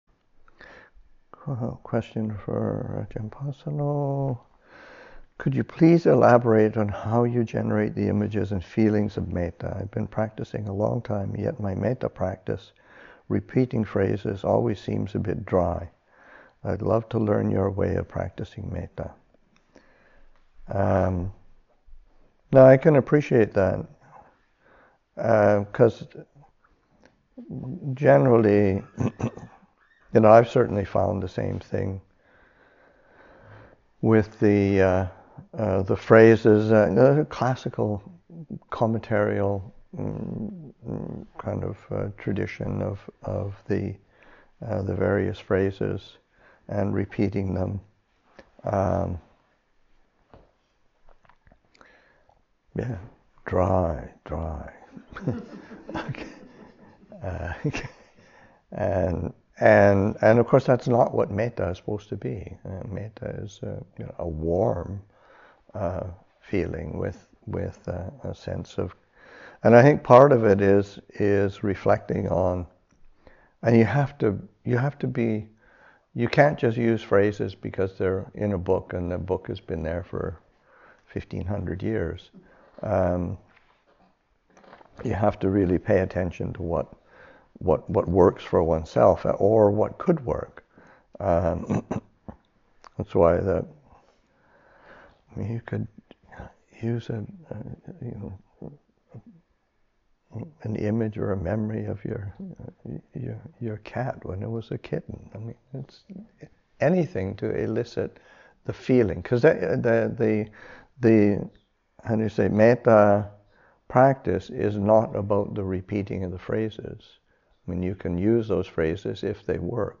Madison Insight Retreat 2023, Session 2 – Oct. 14, 2023